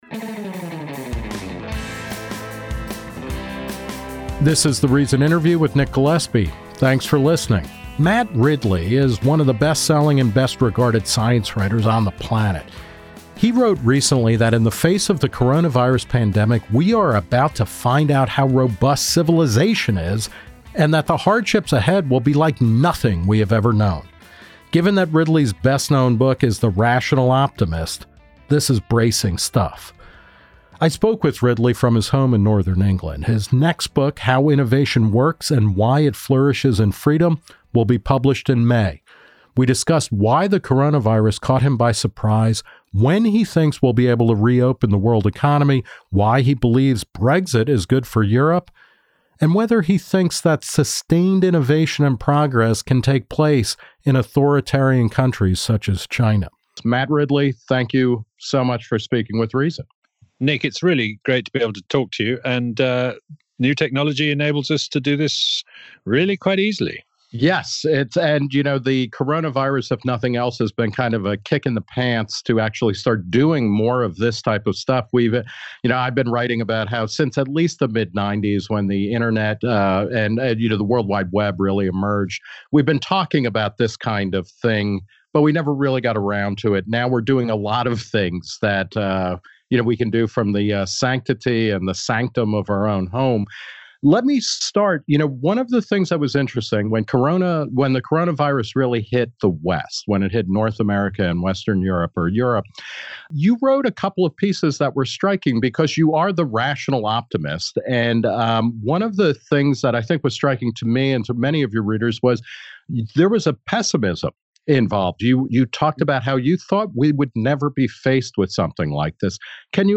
In late March, Reason 's Nick Gillespie spoke with Ridley via Skype from their respective self-quarantines in New York and Northumberland, England. They discussed the political response to COVID-19, Ridley's longstanding distrust of viruses and bats, and when we'll be able to reopen the world economy.